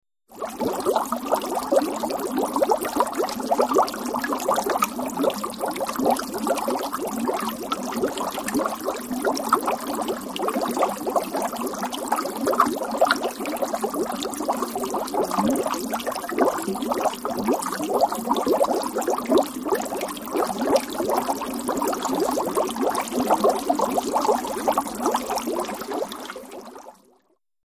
Boiling Liquid
Boiling Liquid; Bubbles 1; A Slow, Rolling Liquid Boil; Consistency Of Water, Crisp High Frequency Sizzle, Close Perspective.